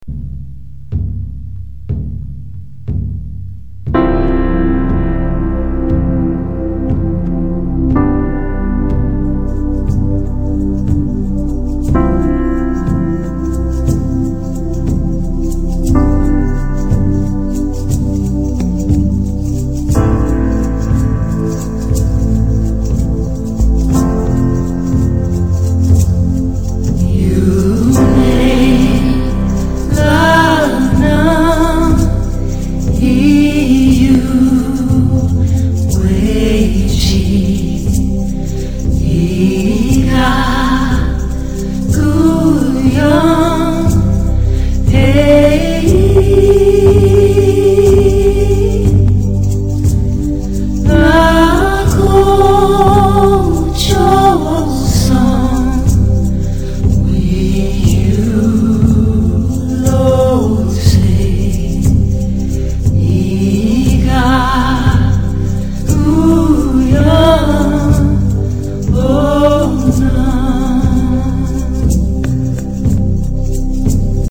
Here’s the first verse of a well-known song in a mystery language.